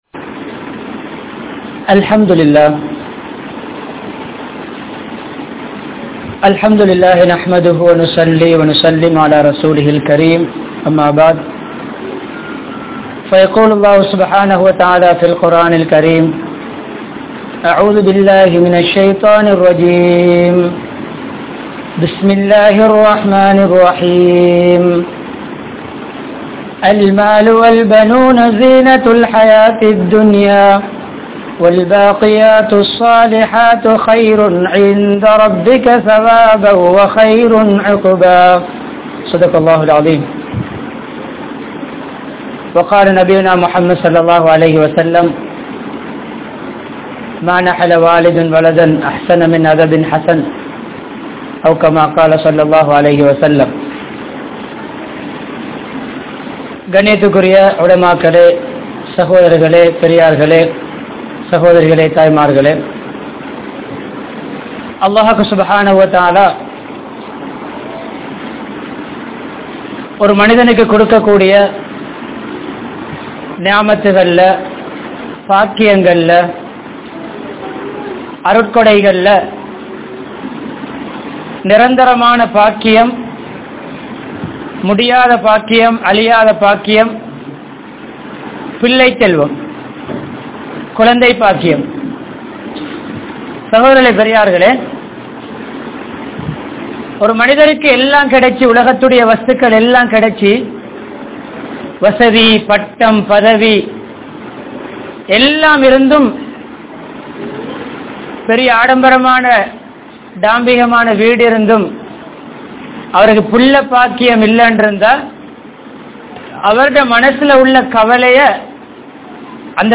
Mun Maathiriyaana Petroarhal (முன்மாதிரியான பெற்றோர்கள்) | Audio Bayans | All Ceylon Muslim Youth Community | Addalaichenai
Wellampitiya, Falooiya Masjidh